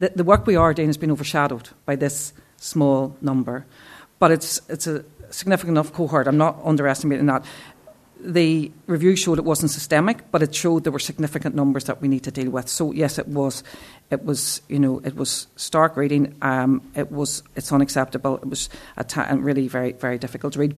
Assistant Commissioner Paula Hillman says the report made for difficult reading………